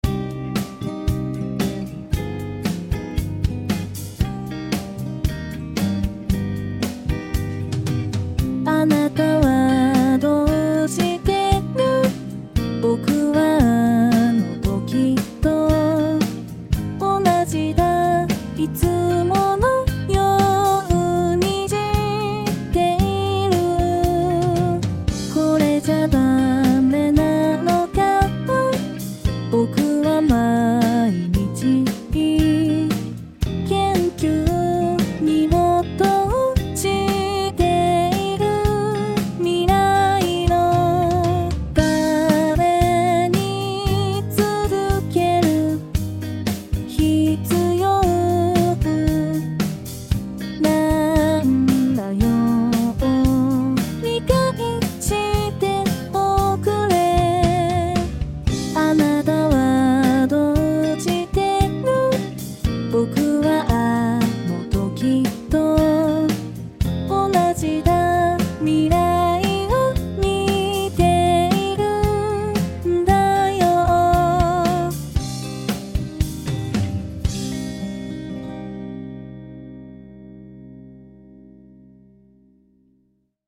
No.01161 [歌]
※Band-in-a-Boxによる自動作曲